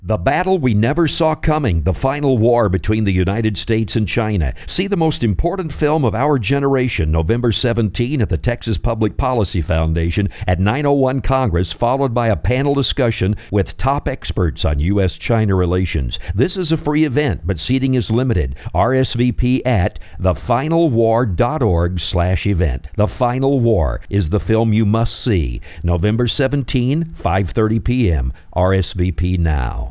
THE FINAL WAR – radio commercial